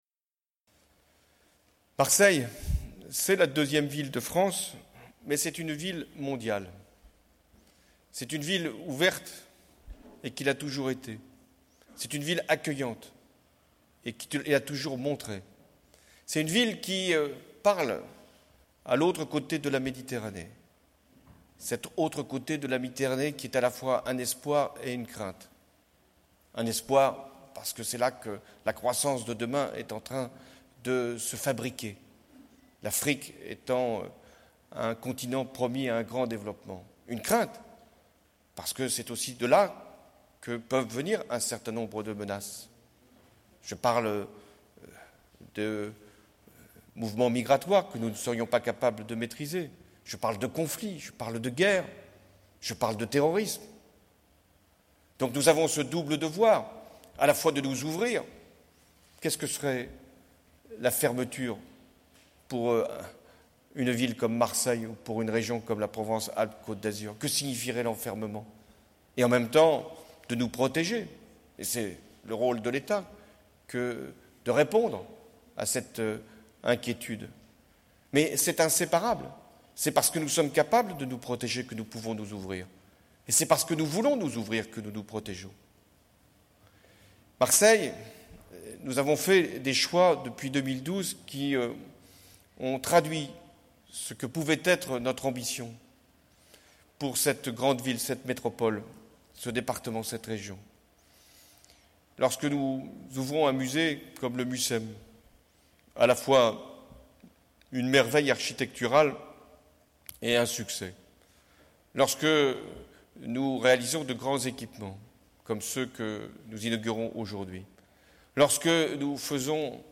François Hollande a inauguré la L2 ce jeudi 8 décembre entouré de Martine Vassal, Jean-Claude Gaudin et Christian Estrosi (Photo Elysée)